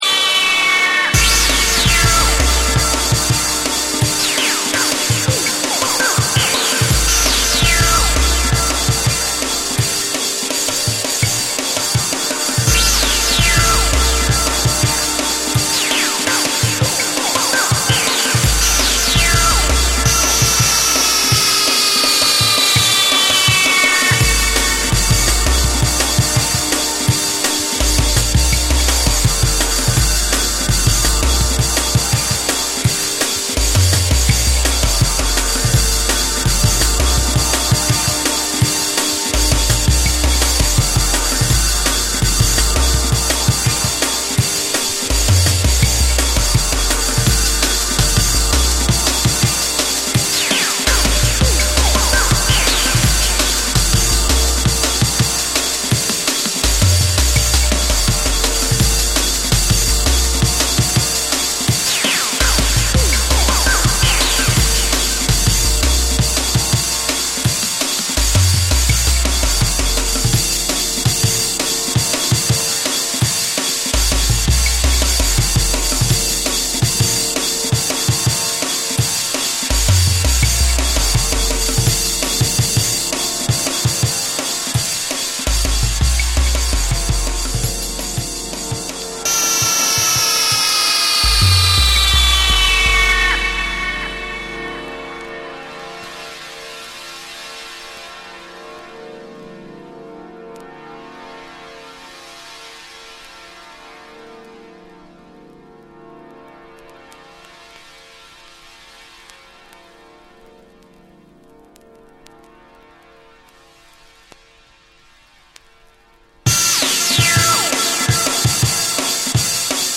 躍動するリズミカルなトラックにスペイシーなシンセが絡み付く
透明感と奥深さを兼ね備えた、上質なドラムンベースを収録。
JUNGLE & DRUM'N BASS